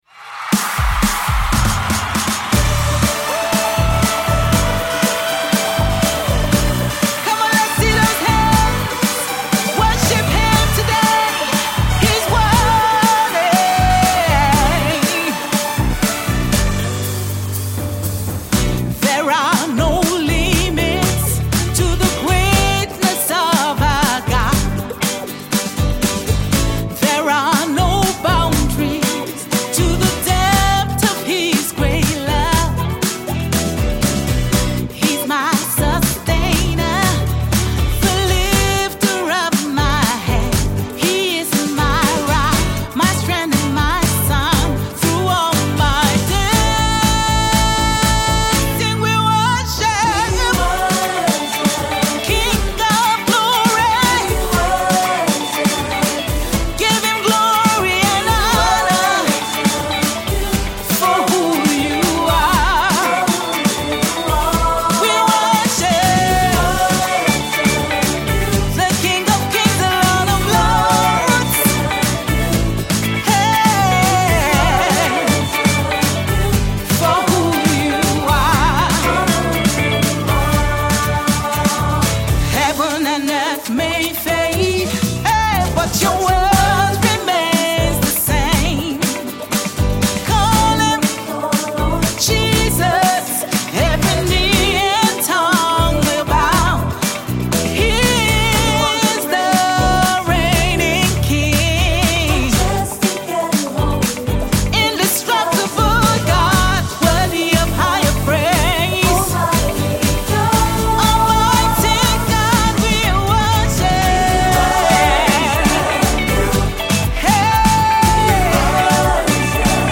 Gospel music minister and songwriter